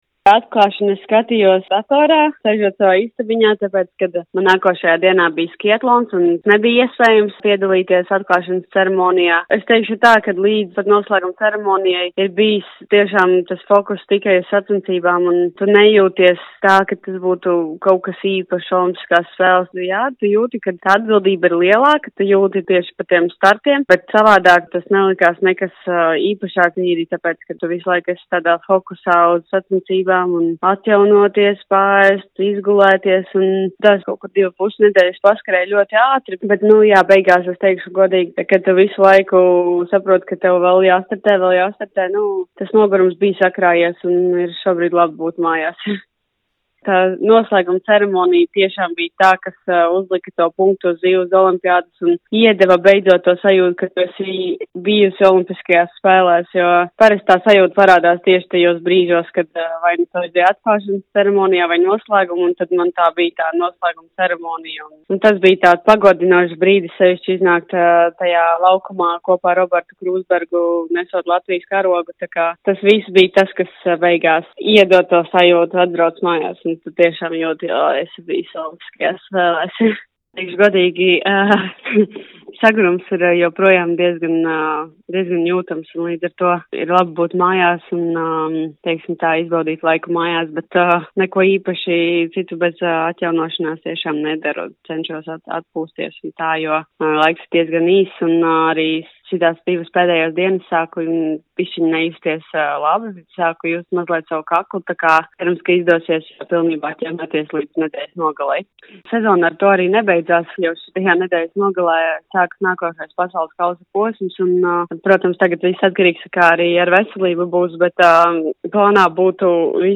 Intervijā Skonto mediju grupai Eiduka dalījās iespaidos par piedzīvoto Milānas Kortīnas ziemas Olimpiskajās spēlēs.
Distanču slēpotāja Patrīcija Eiduka